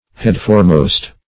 Search Result for " headforemost" : The Collaborative International Dictionary of English v.0.48: Headfirst \Head`first"\ (h[e^]d"f[~e]rst`), Headforemost \Head`fore"most`\ (h[e^]d`f[=o]r"m[=o]st`), adv.